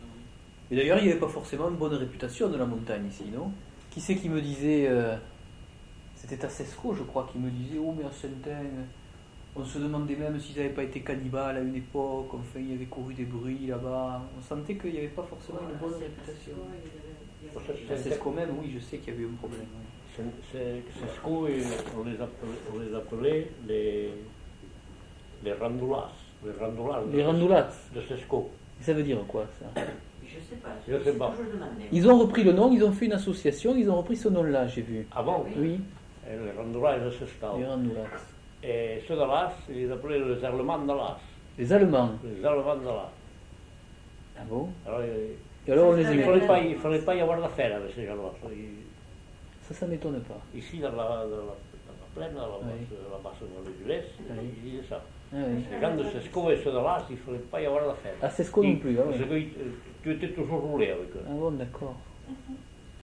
Lieu : Pouech de Luzenac (lieu-dit)
Genre : témoignage thématique